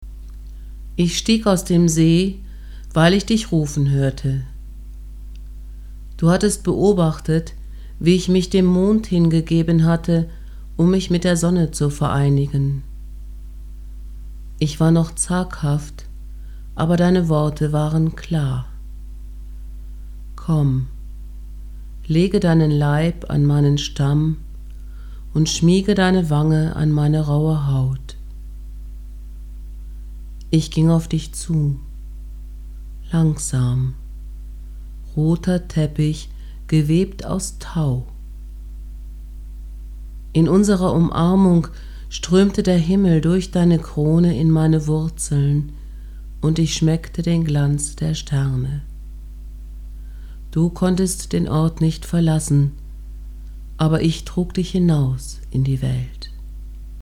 online Lesung